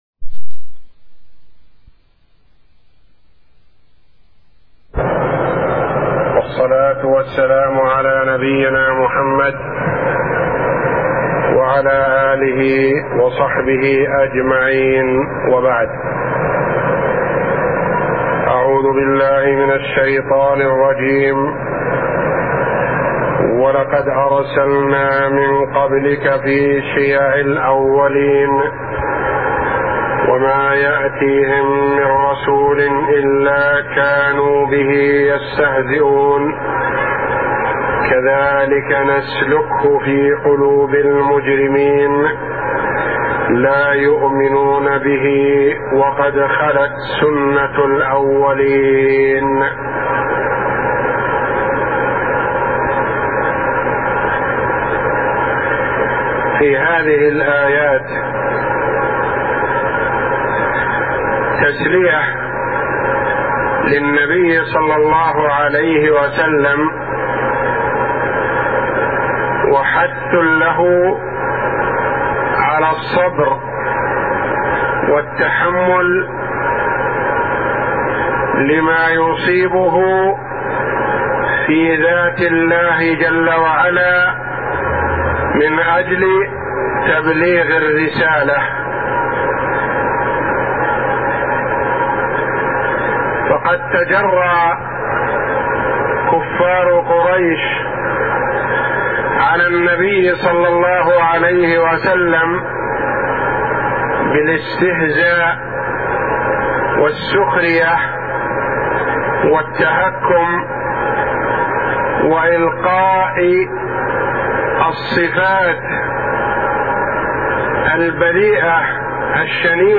من دروس الحرم المكى الشريف